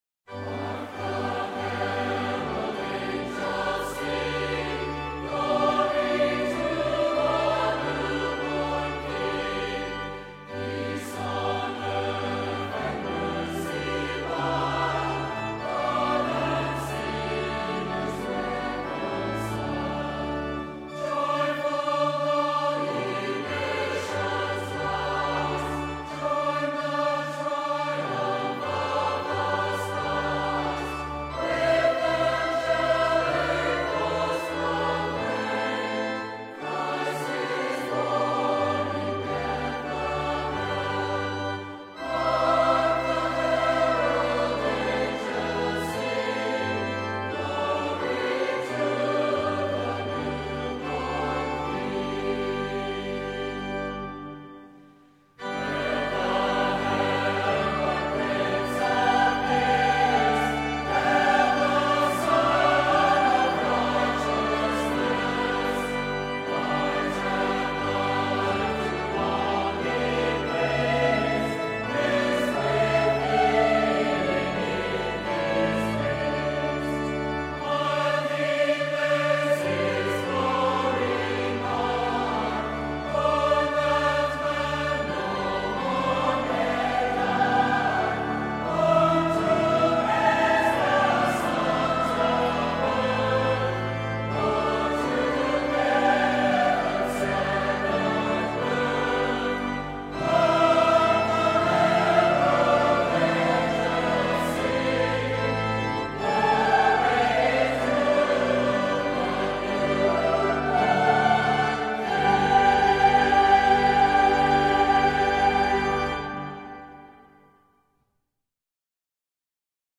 Recorded October 21, 1995 at the Provo Utah Central Stake center, the Choir for the 1995 Christmas concert numbers approximately eighty singers with most of the stake's twelve wards represented.